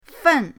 fen4.mp3